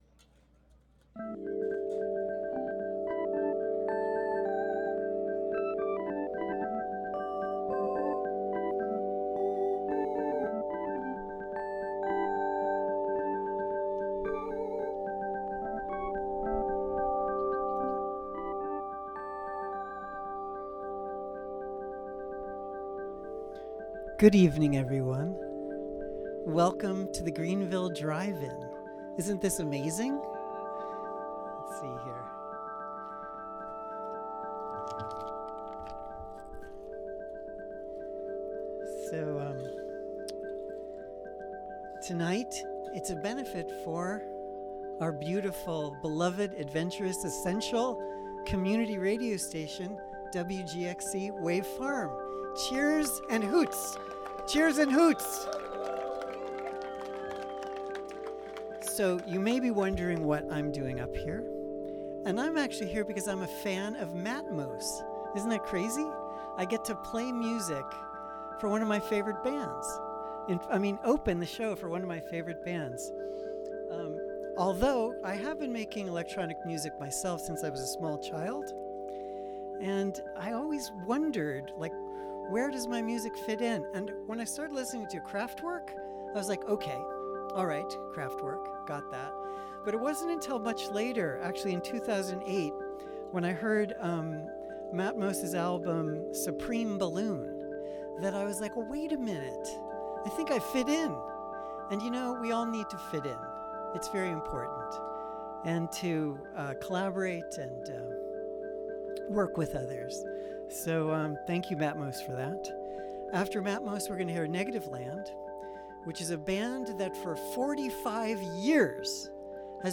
synthesizer beeps